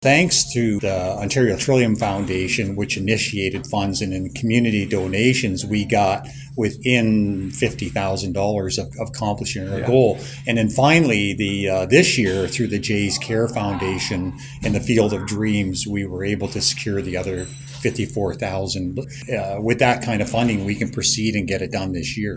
Total estimated cost of the upgrade is $150,000 and this Jays Care Foundation grant is the final piece in the funding puzzle, explained Dutton/Dunwich Coun. Henry Dryfhout.